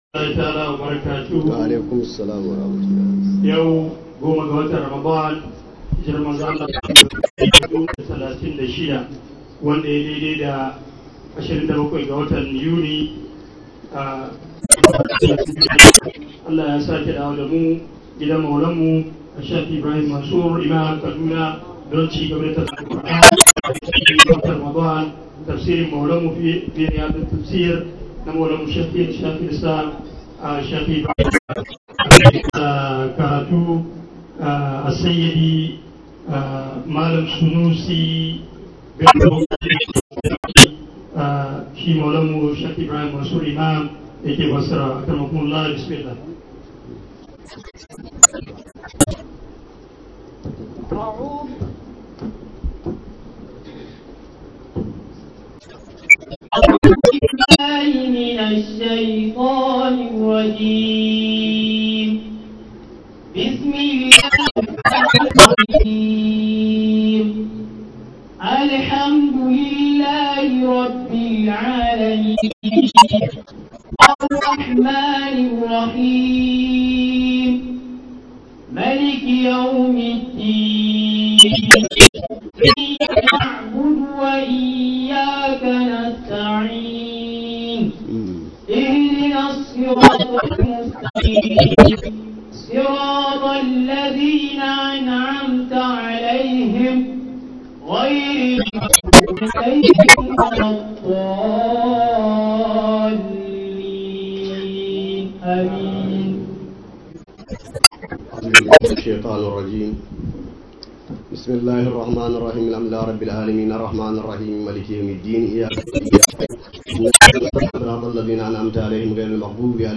002 HAYATUDEEN ISLAMIC STUDIO TAFSIR 2015.mp3